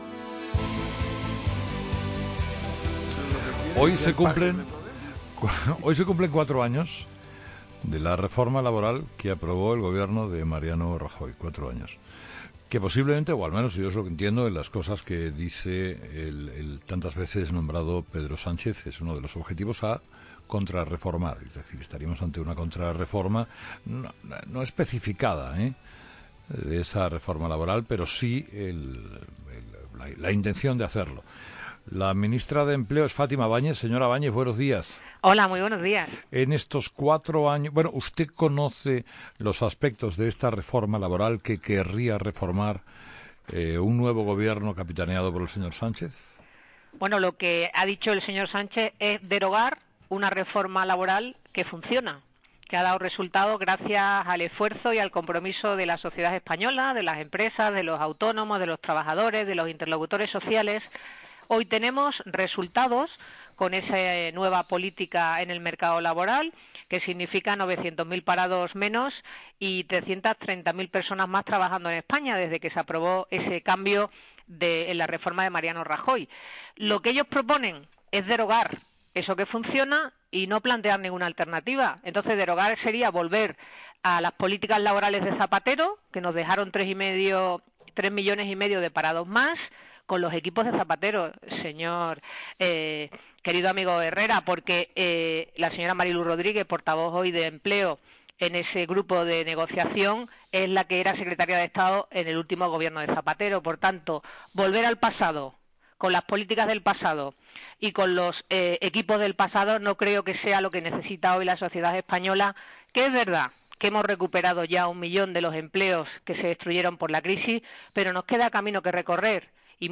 Entrevista con Fátima Báñez COPE
Entrevistado: "Fátima Báñez"